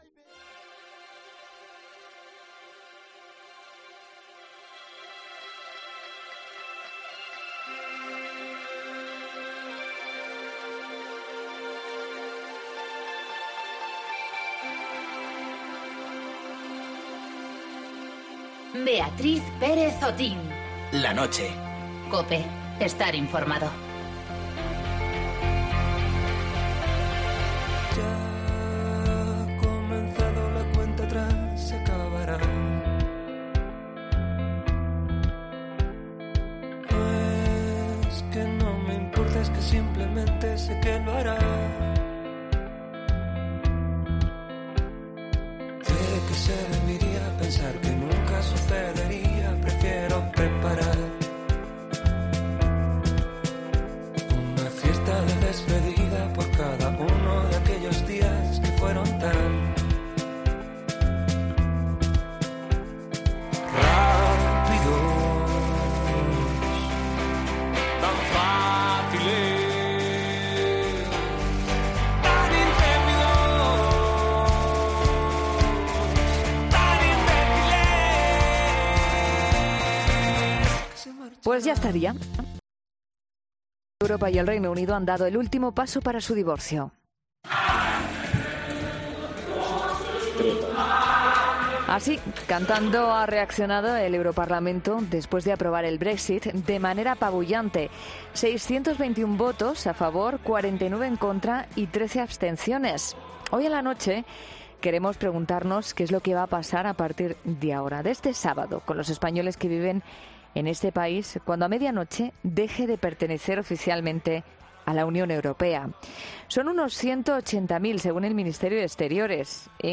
ESCUCHA ESTAS ENTREVISTAS EN 'LA NOCHE' CLICANDO AQUÍ